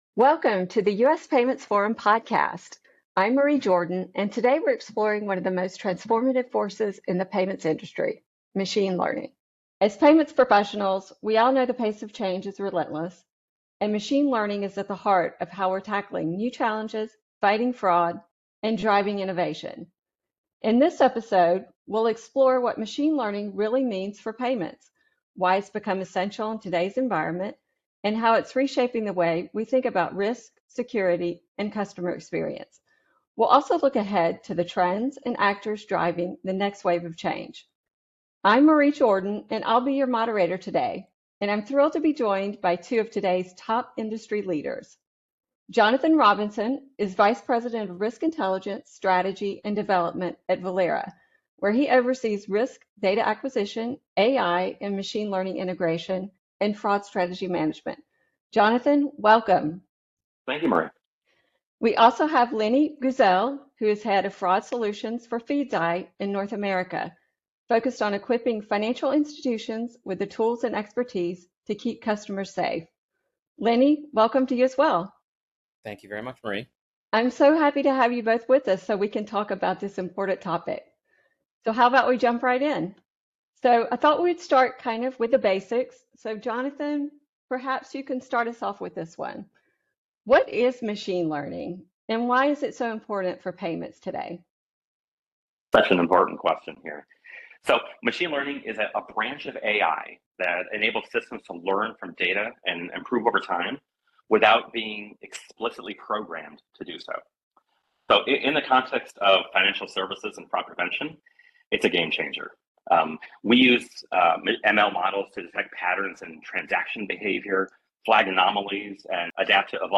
In this podcast episode, industry experts explore how machine learning is being applied today to detect fraud, reduce false positives, and adapt to evolving threats in real time.